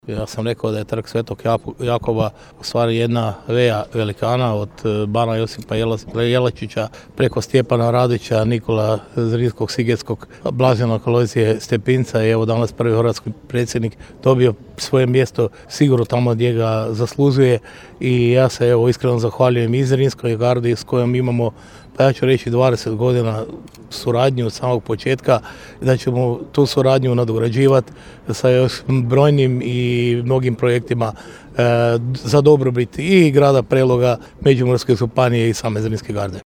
Izjava gradonačelnika Ljubomira Kolareka: